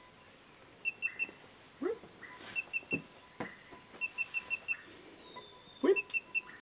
Short-tailed Antthrush (Chamaeza campanisona)
Life Stage: Adult
Country: Argentina
Location or protected area: Bio Reserva Karadya
Condition: Wild
Certainty: Recorded vocal